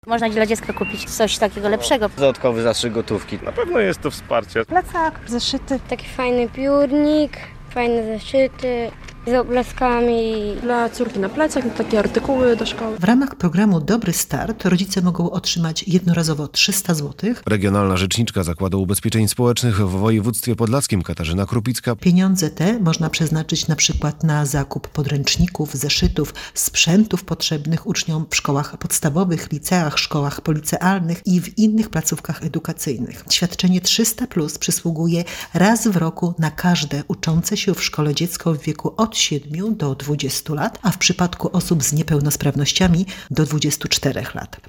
Rodzice przyznają, że 300 zł to znacząca pomoc - relacja